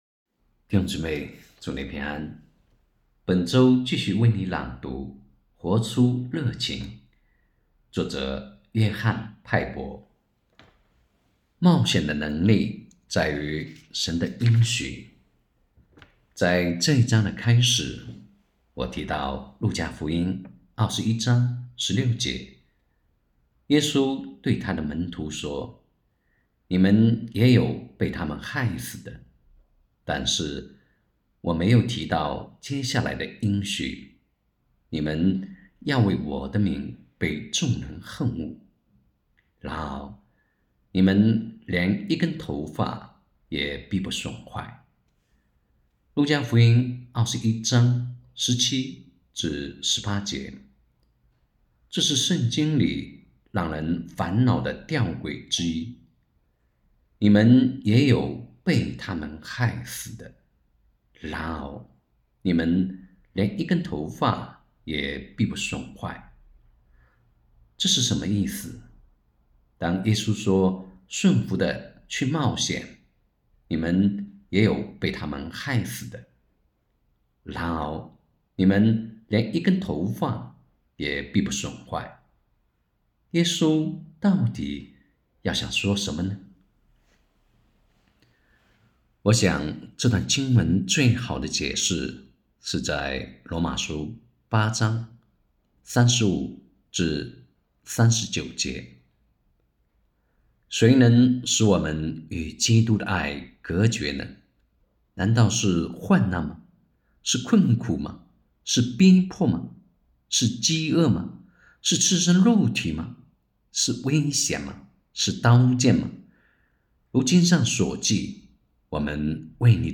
2024年3月1日 “伴你读书”，正在为您朗读：《活出热情》 欢迎点击下方音频聆听朗读内容 https